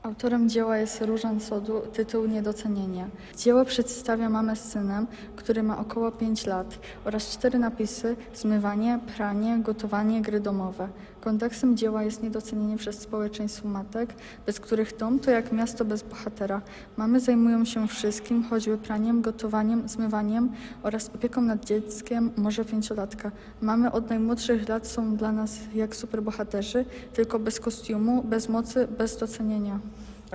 Moving Image. Intervention: Audioguide in Polish